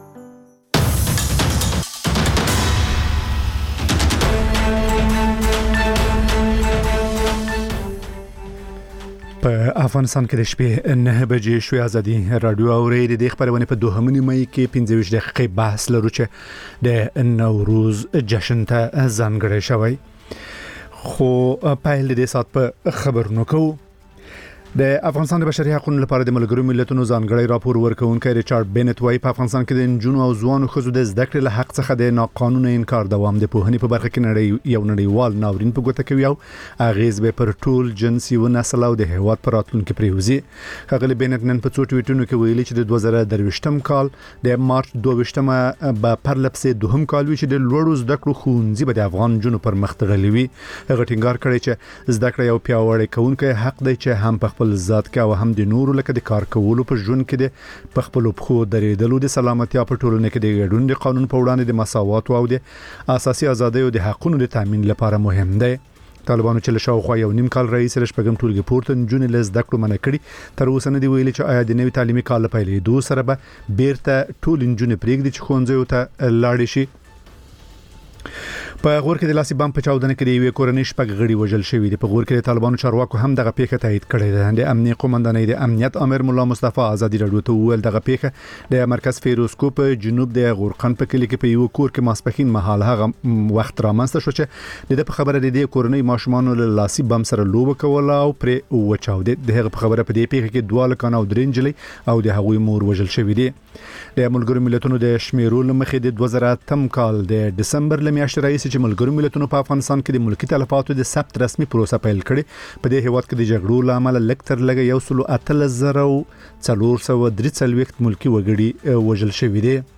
ماخوستنی خبري ساعت